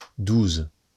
wymowa:
IPA[duz] ?/i